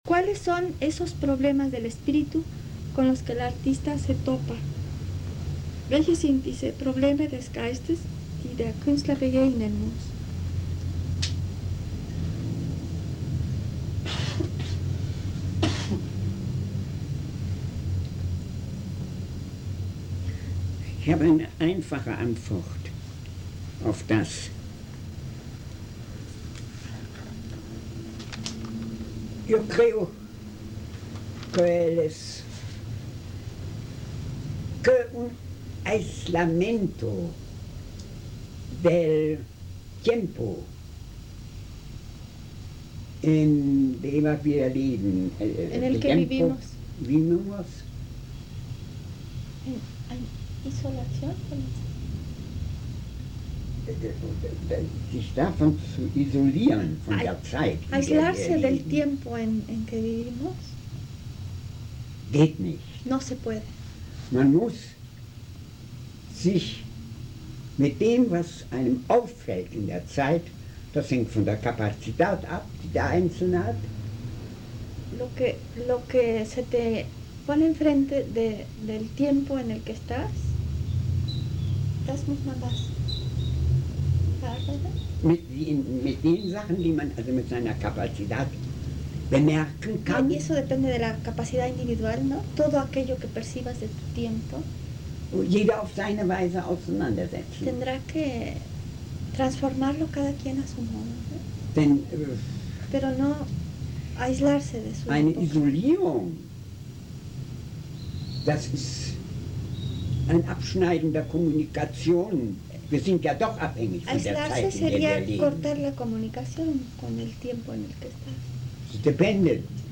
Las grabaciones se realizaron en el taller de pintura con jóvenes del barrio de Tepito, en la Casa de Cultura Enrique Ramírez y Ramírez, en el año de 1984.